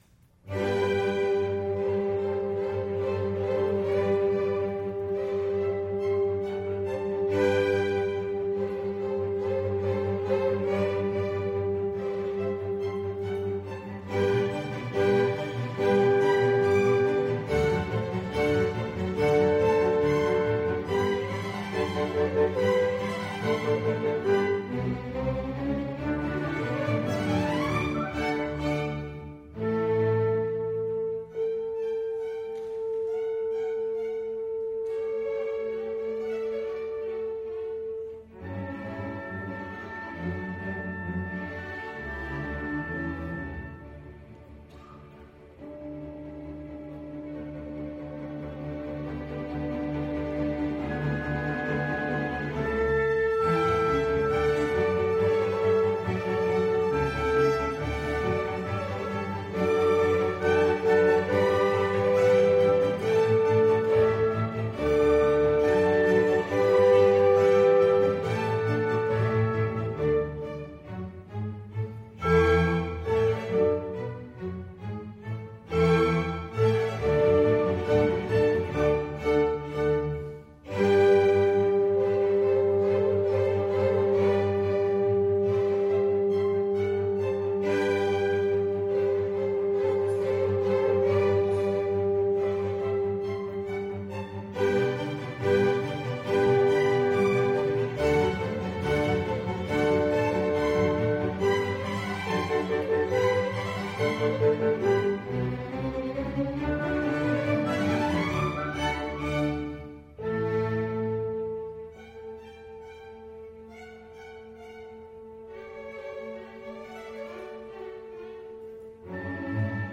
Orchestra
Oboes Horns Violin 1 Violin 2 Viola Cellos and Basses
Style: Classical
Audio: Fukuoka, Japan - Das Orchester Tsumugi